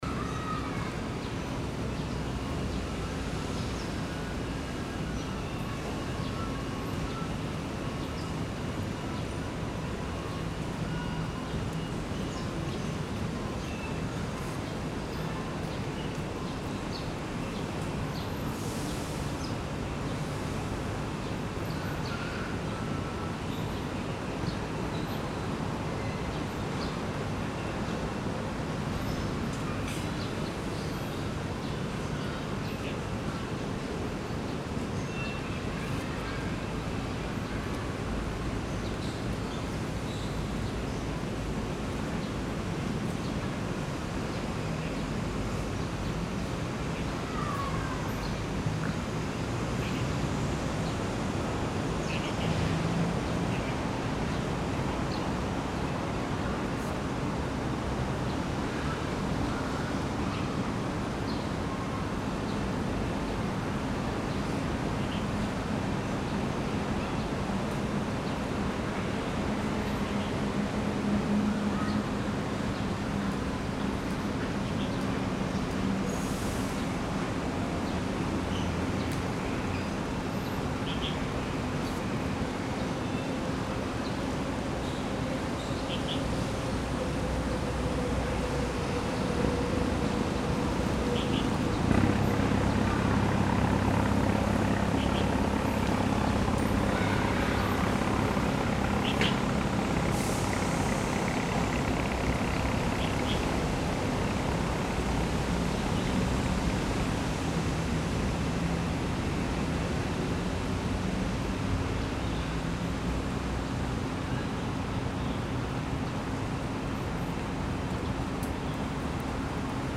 The Aberdeen Ferry’s Aberdeen to Ap Lei Chau Kaito route operates between Marina Habitat in Ap Lei Chau and the Aberdeen West Typhoon Shelter, operated by the Aberdeen Ferry Company. The recording was made at the location just before entering the pier at Aberdeen Promenade. You can hear passengers stepping onto the plank connecting to the pier, as well as children playing in the park and the sounds of the water surface in Aberdeen Harbour.
錄音器材 Recording Device: Tascam Portacapture X8 w/ Clippy EM272
錄音於香港仔海濱公園進入碼頭前位置錄製，可以聽到乘客踏上連接碼頭的踏板，以及小孩在公園玩耍、香港仔灣的水面聲音。